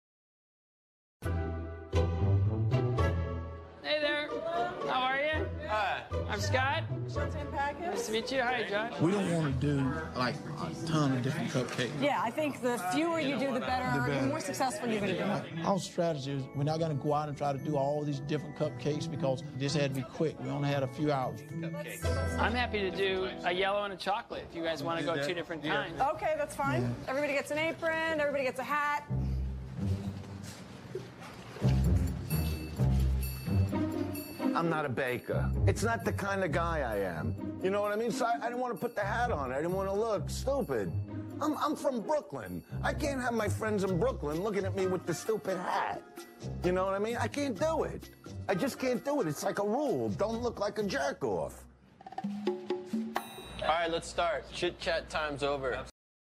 在线英语听力室影视剧中的职场美语 第45期:汇报工作的听力文件下载,《影视中的职场美语》收录了工作沟通，办公室生活，商务贸易等方面的情景对话。